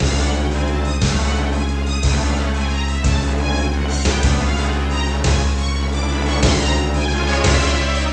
escape2_floor_falll.WAV